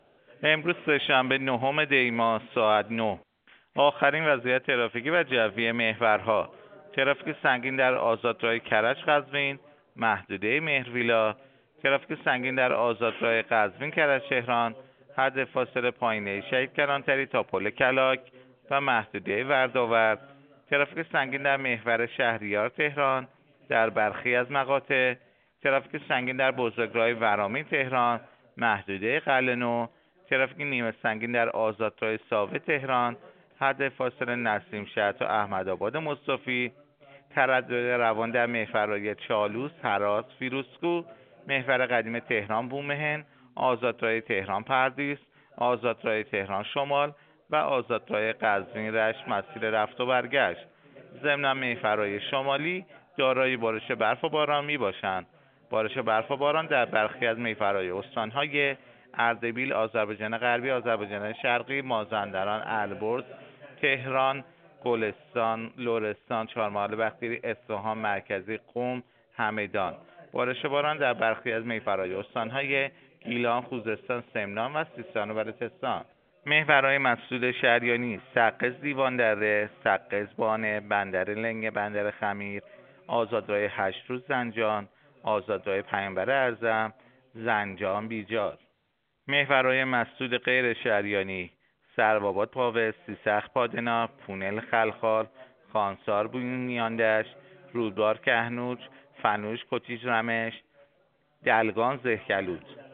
گزارش رادیو اینترنتی از آخرین وضعیت ترافیکی جاده‌ها ساعت ۹ نهم دی؛